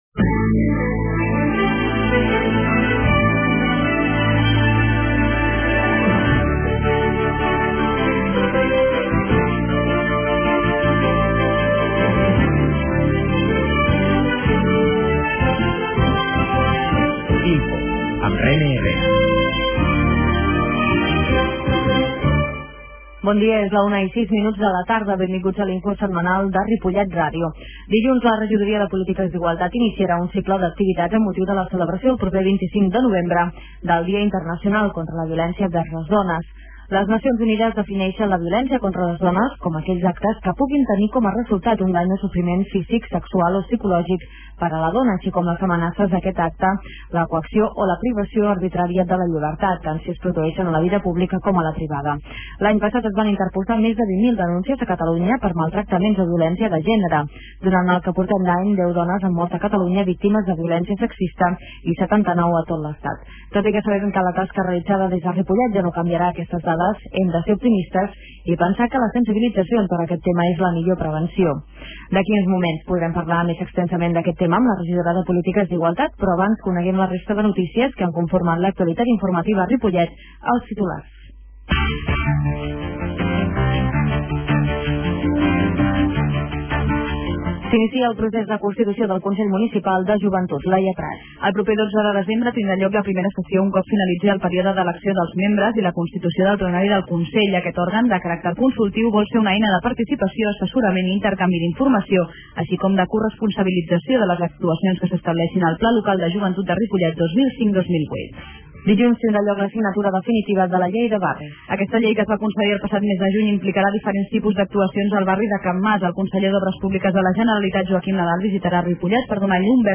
La qualitat de s� ha estat redu�da per tal d'agilitar la seva desc�rrega.